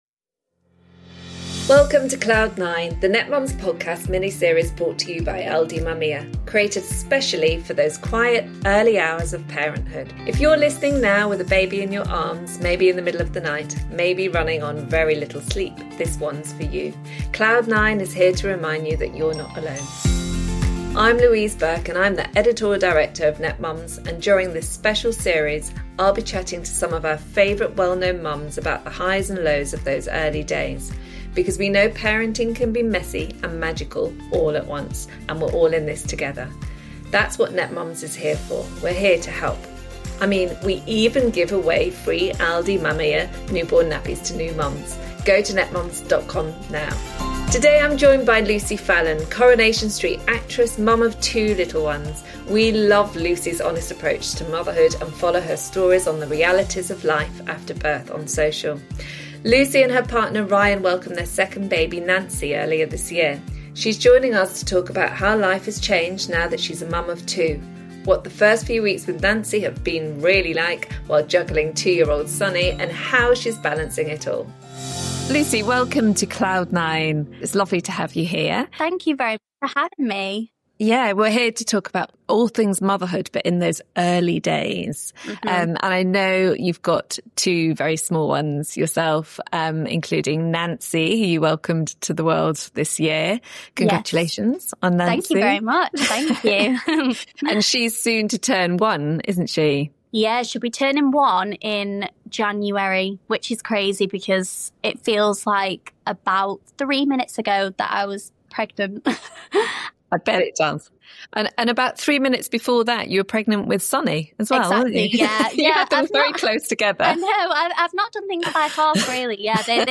In this episode we’re joined by the fabulous Lucy Fallon, the award-wining actress from Coronation Street and a mum of two little ones.